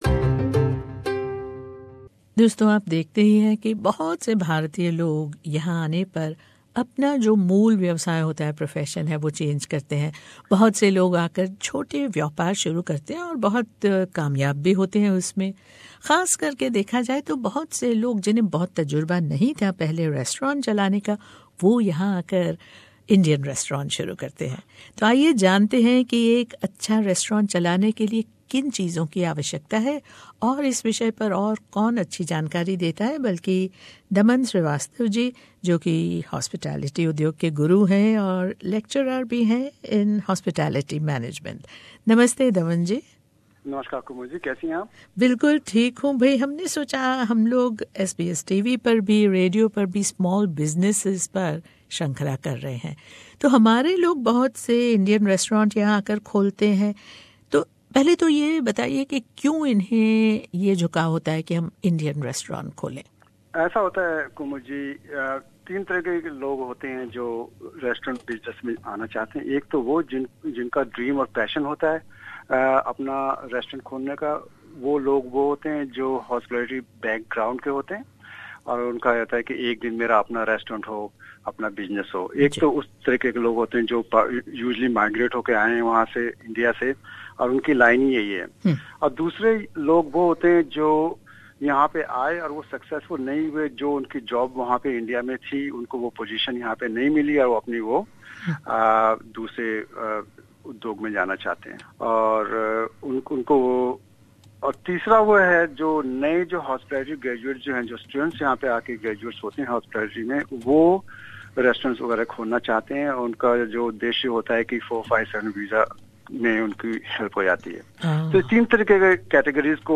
प्रस्तुत है उनकी भेंटवार्ता